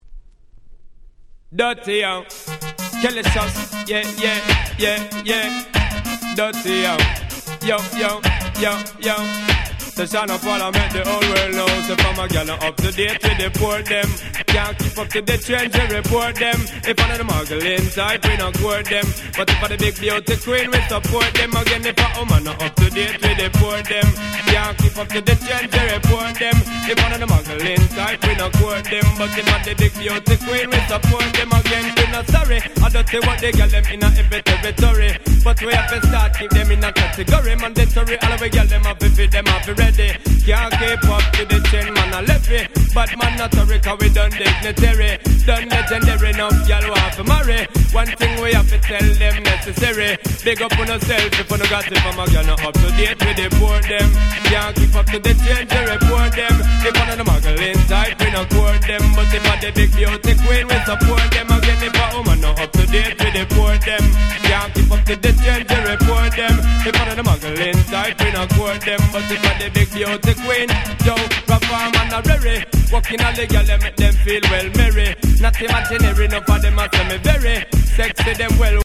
90's Dancehall Reggae Classic Riddim !!